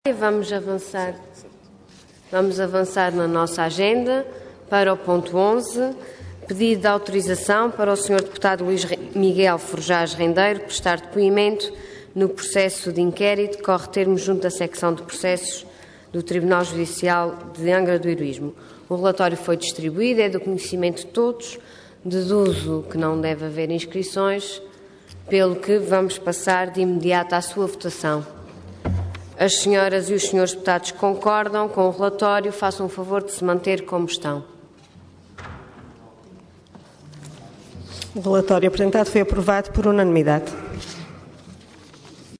Assembleia Legislativa da Região Autónoma dos Açores
Intervenção
Presidente da Assembleia Regional